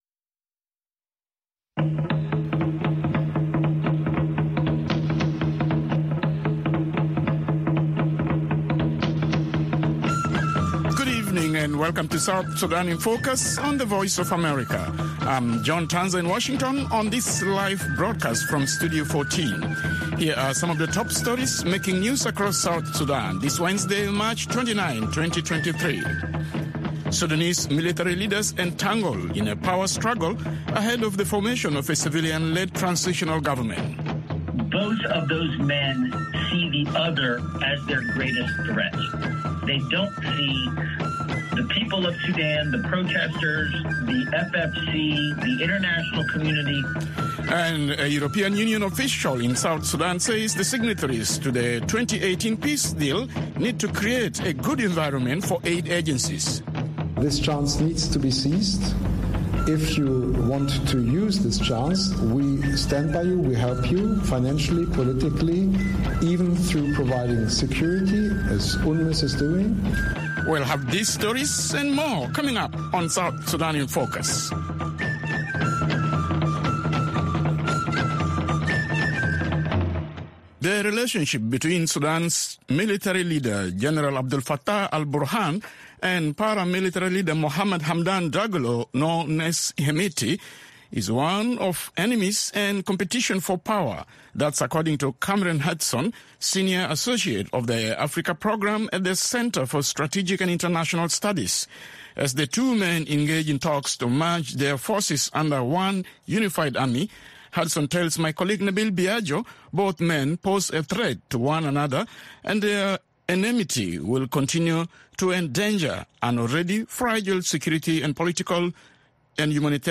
South Sudan in Focus airs at 7:30 pm in Juba (1630 UTC) and can be heard on FM stations throughout South Sudan, on shortwave, and on VOA’s 24-hour channel in Nairobi at 8:30 pm.